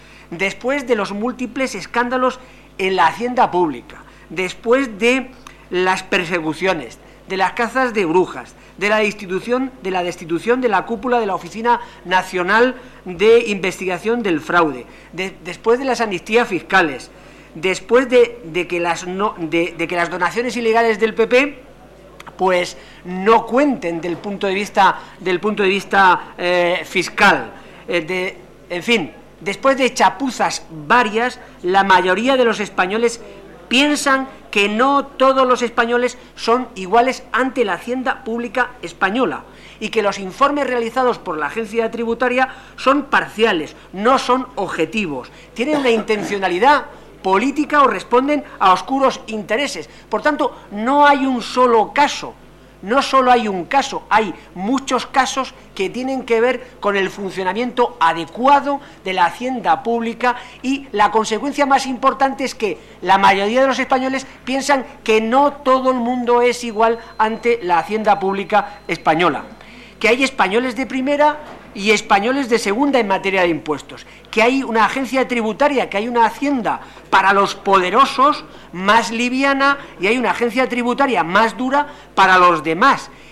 Pedro Saura. Diputación Permanente del 14/01/2014. Petición de comparecencia del ministro de Hacienda sobre el caso Nóos